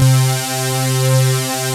LYRLEAD3.wav